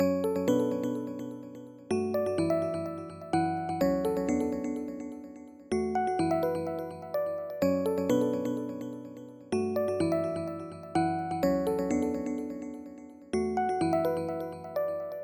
描述：采用果味循环，酷炫的陷阱钟声
Tag: 130 bpm Trap Loops Bells Loops 1.24 MB wav Key : E